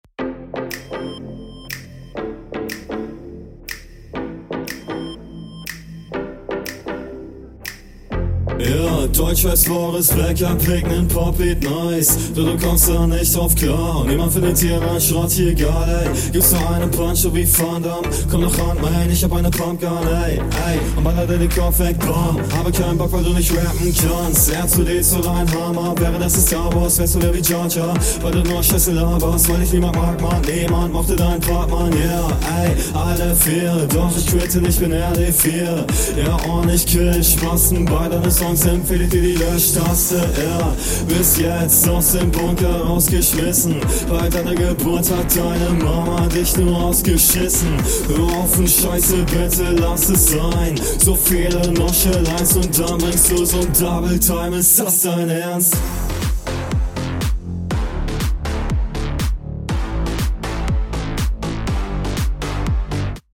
was mir hier als erstes auffällt ist die grauenvolle mische, das klingt sehr unangenehm, vor …
Tja das ist ein Beat für dich.